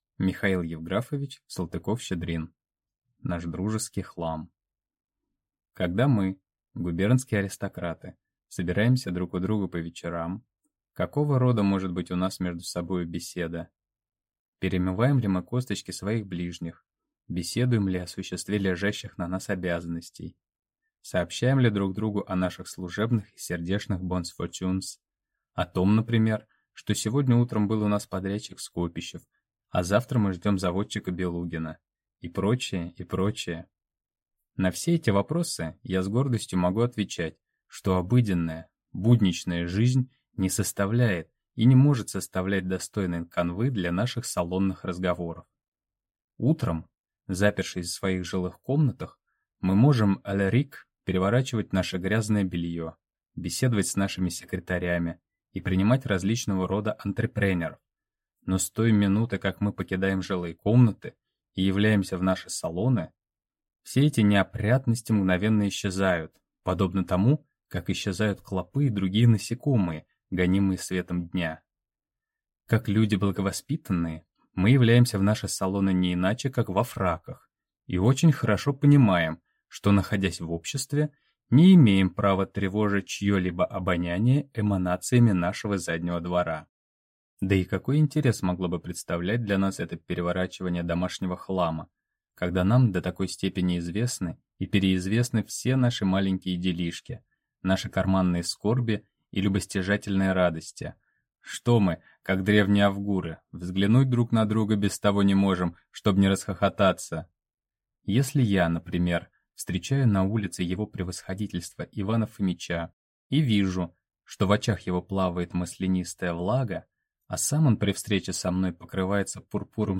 Аудиокнига Наш дружеский хлам | Библиотека аудиокниг